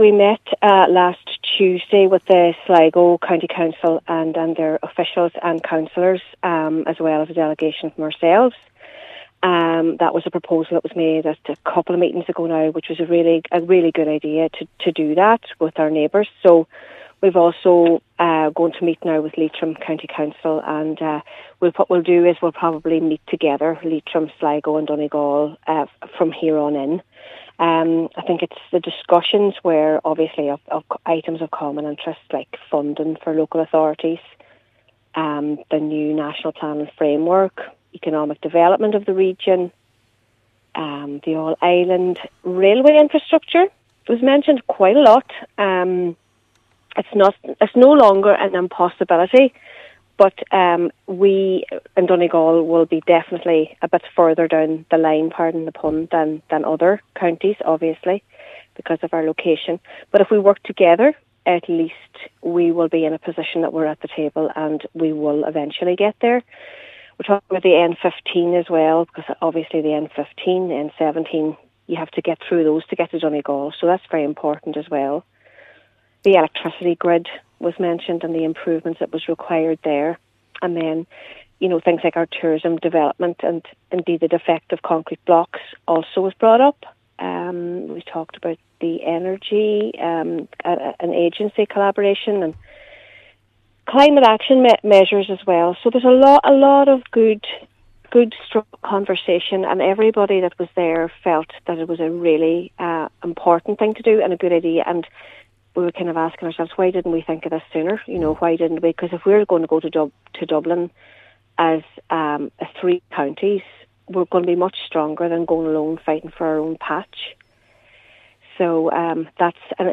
Donegal County Council Cathaoirleach Cllr. Niamh Kennedy says it’s important that the North West counties are seen to present a united front…………